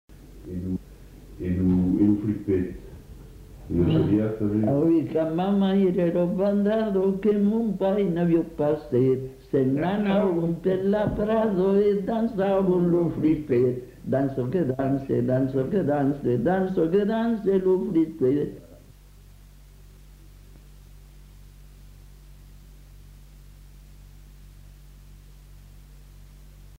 Aire culturelle : Haut-Agenais
Genre : chant
Effectif : 1
Type de voix : voix d'homme
Production du son : chanté
Danse : pripet